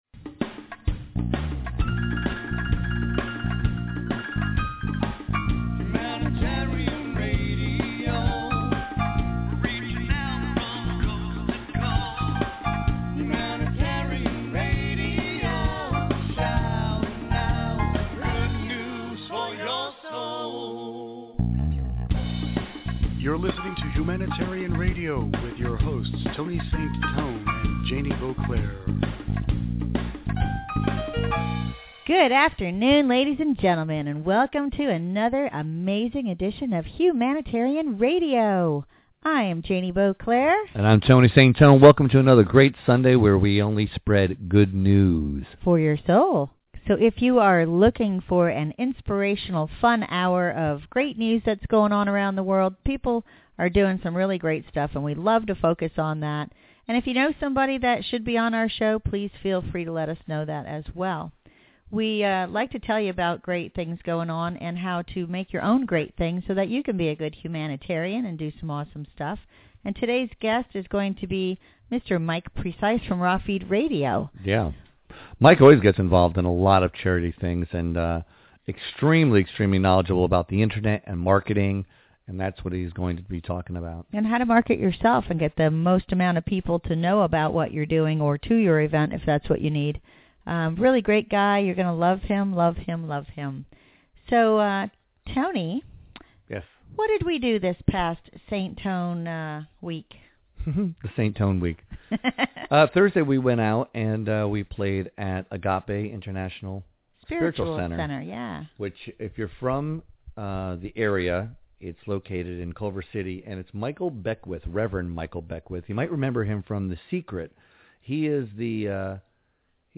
Entertainment HR Interview